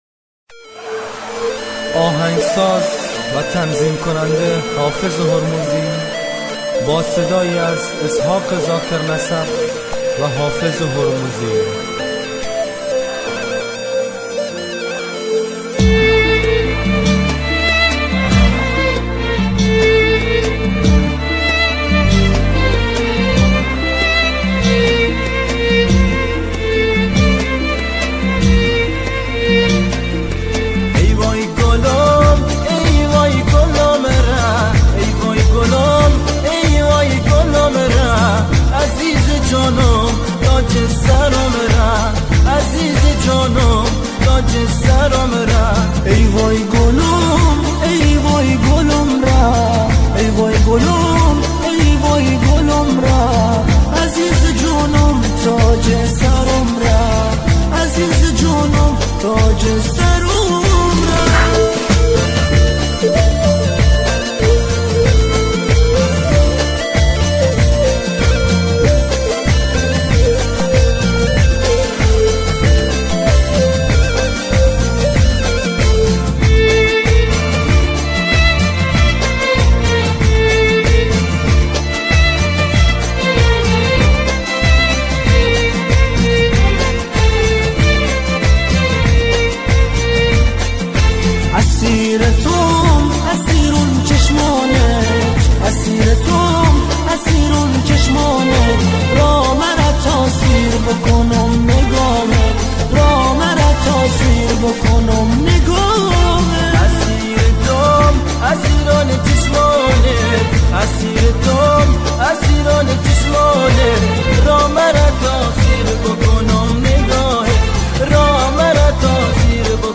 اهنگ بندری